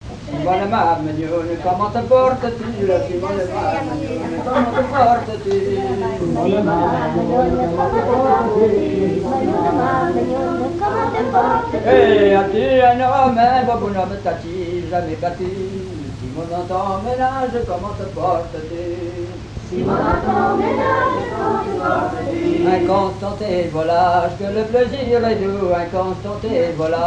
danses à l'accordéon diatonique et chansons, veillée à l'Abbaye d'Orouet
Pièce musicale inédite